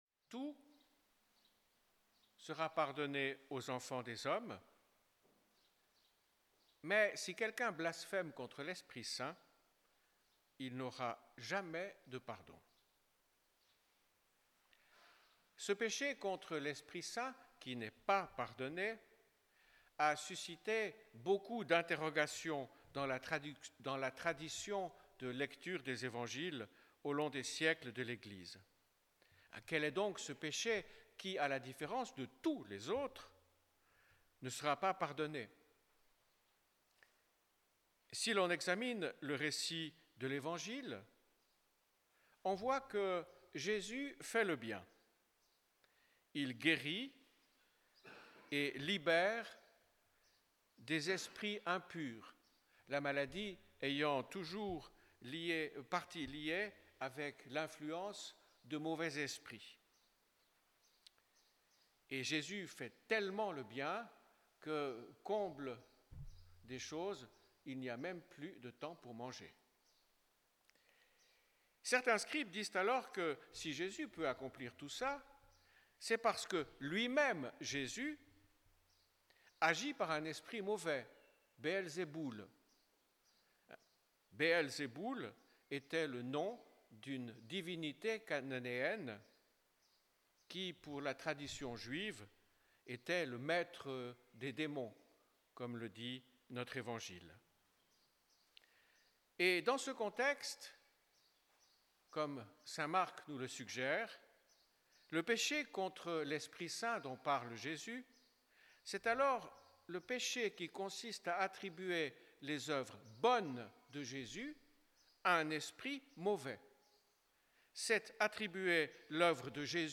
Enregistrement : l'homélie